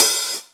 paiste hi hat7 half.wav